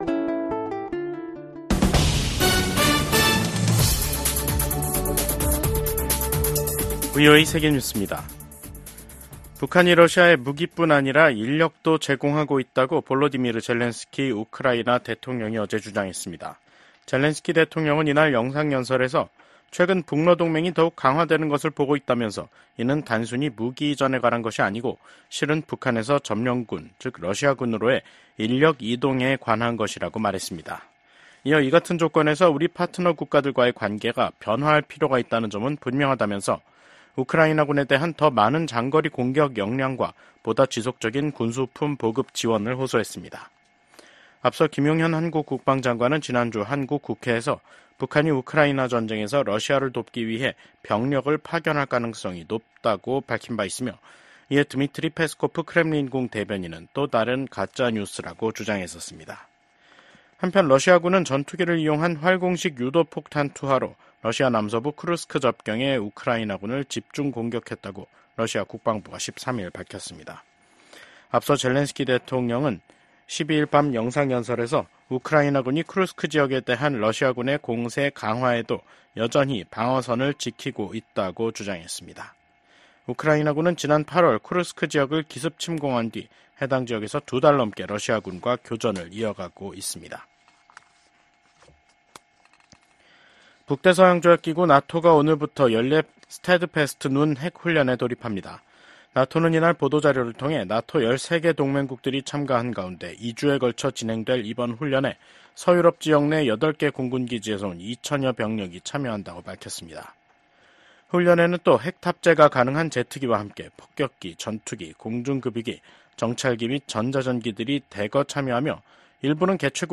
VOA 한국어 간판 뉴스 프로그램 '뉴스 투데이', 2024년 10월 14일 2부 방송입니다. 북한이 한국 측 무인기의 평양 침투를 주장하면서 한국과의 접경 부근 포병 부대들에게 사격 준비 태세를 지시했습니다. 미국 북한인권특사는 북한에서 공개재판과 공개처형이 늘어나는 등 북한 인권 실태가 더욱 열악해지고 있다고 지적했습니다.